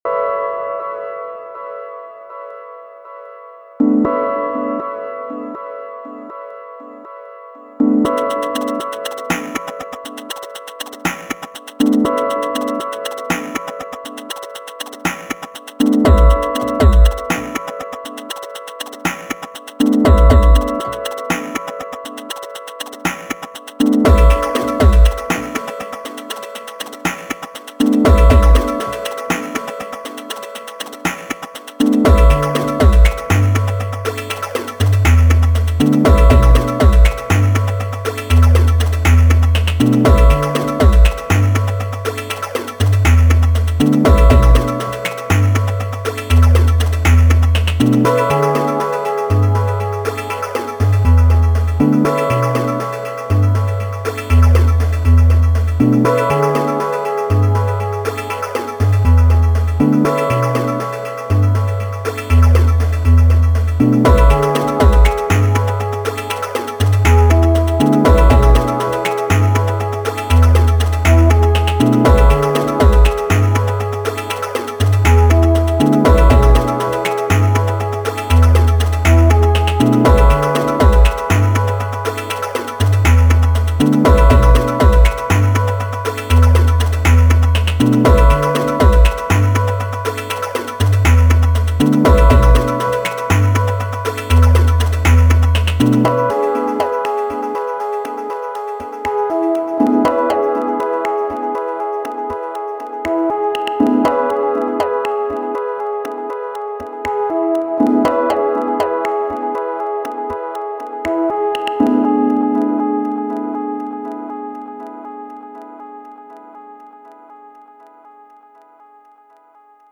Been having some fun with my Syntakt and some modular gear in the countryside. Had some days doing more downtempo stuff but kicked the tempo up a bit on this last jam: